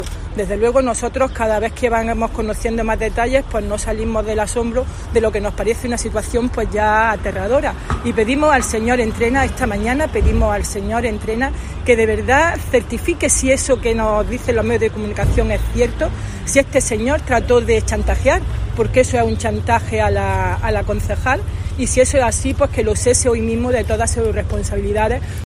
Carmen Lidia Reyes, portavoz del PP en Diputación